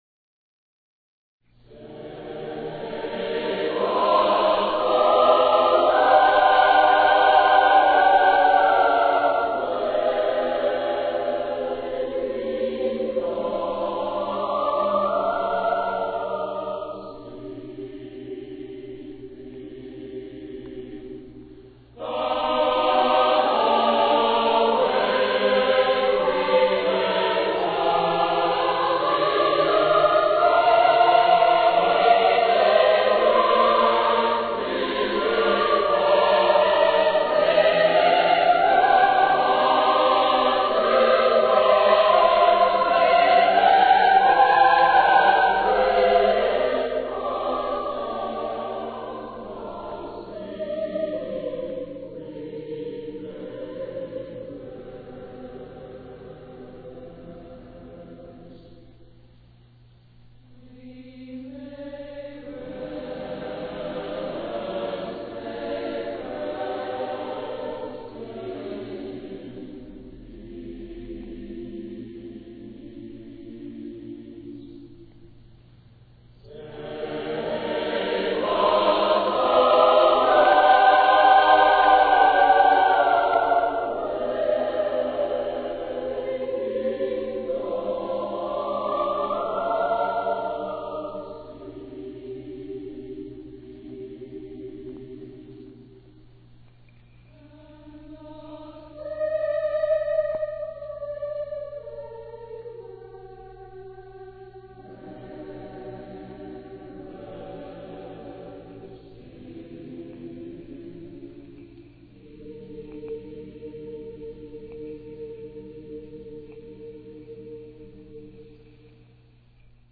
MIXED CHORUS AND GIRLS GLEE CLUB
Performed by The Edison High School Concert Chorus, Mixed Chorus and Girls Glee Club
Recorded at Trinity Episcopal Church, Tulsa, Oklahoma
organ